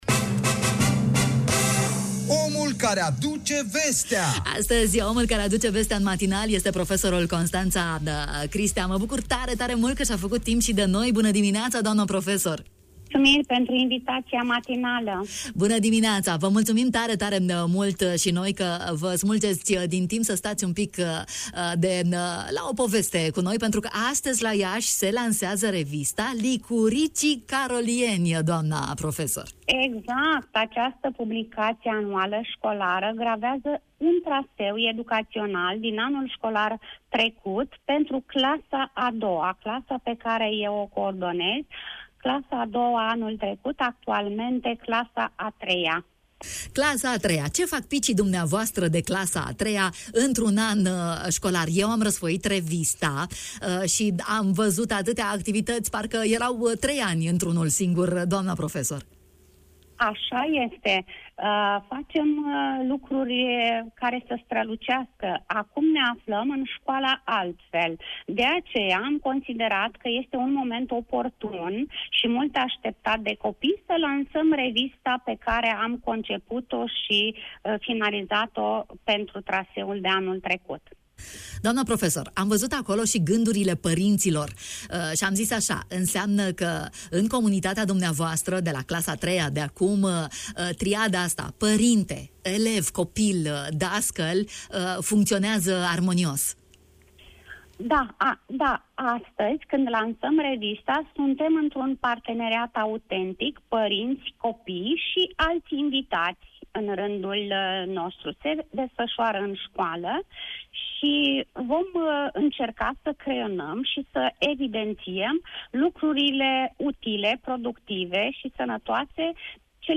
în direct în matinalul Radio România Iași: